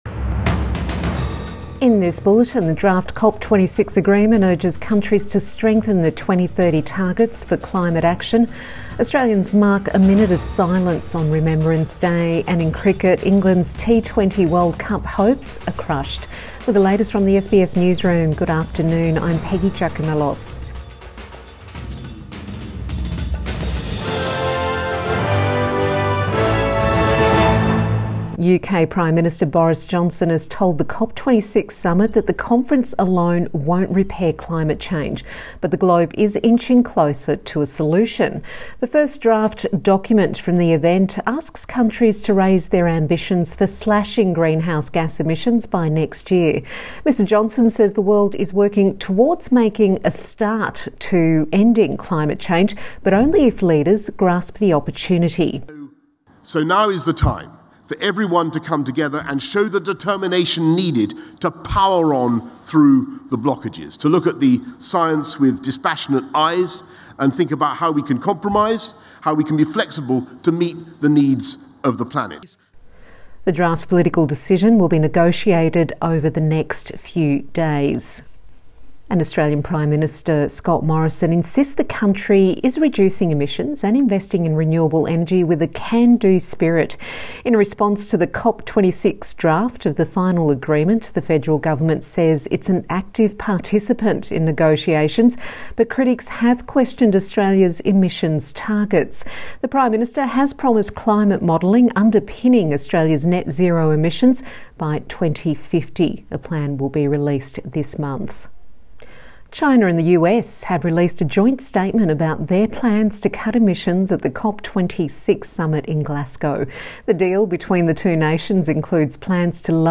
Midday bulletin November 11 2021